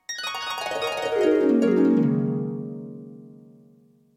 Oriental Harp 2.
bonus-sound film-production game-development harp intro oriental oriental-harp sound effect free sound royalty free Movies & TV